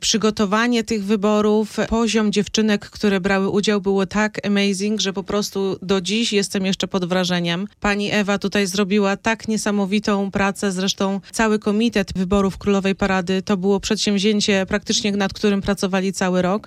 GOŚCIE BUDZIK MORNING SHOW